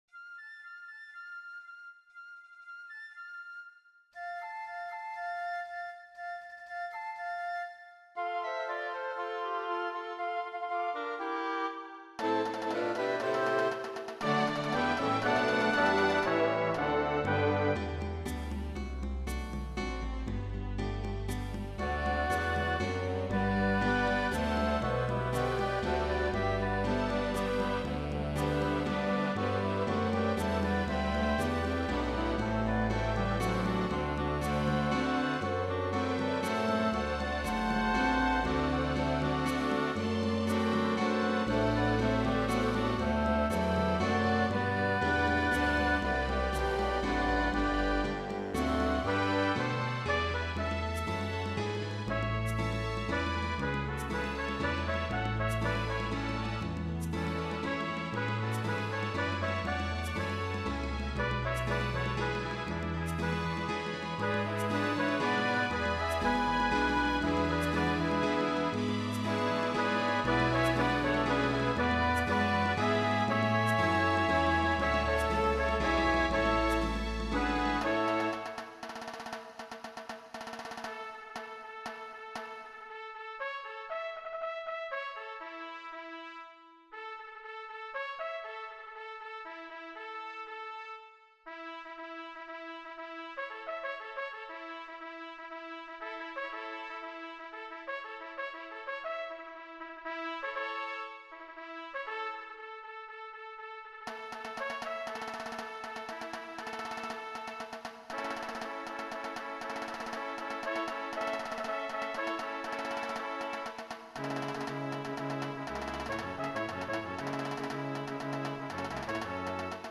a work for orchestra
3. U.S. Army comes with a drum roll and bugle call